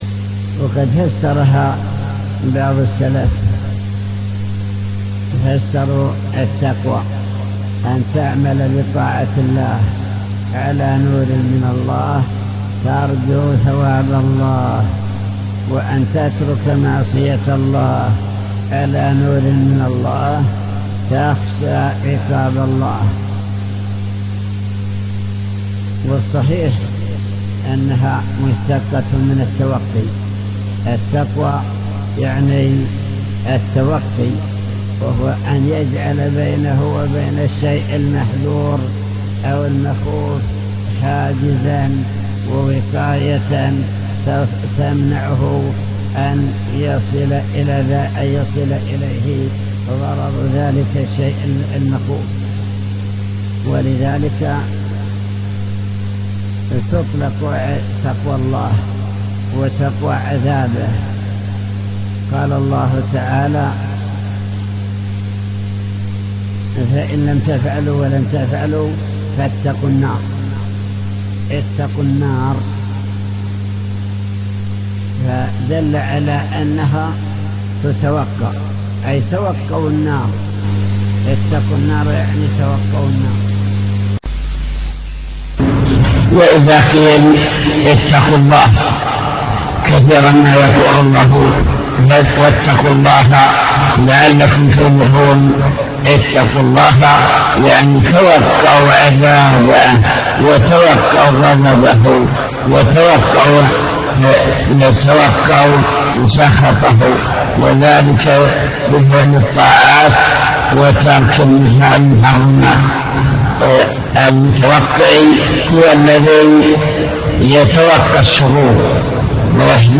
المكتبة الصوتية  تسجيلات - كتب  شرح كتاب بهجة قلوب الأبرار لابن السعدي شرح حديث اتق الله حيثما كنت